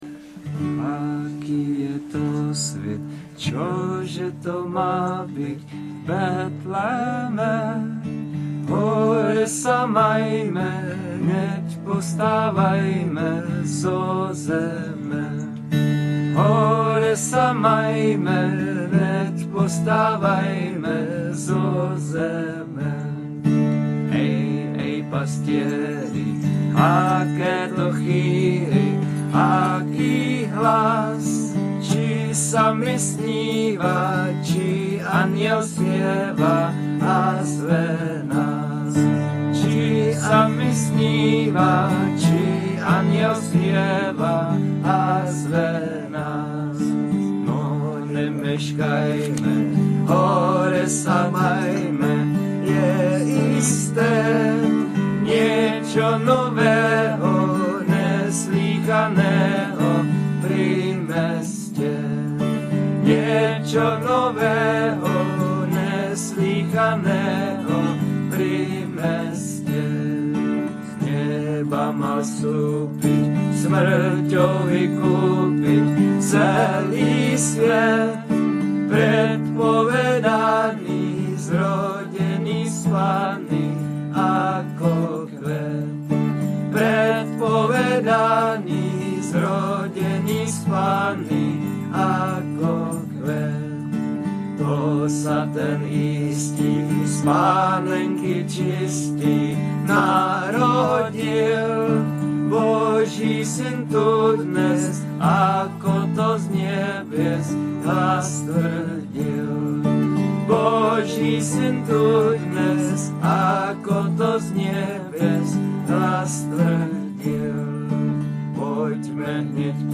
nahrávka ze zpívání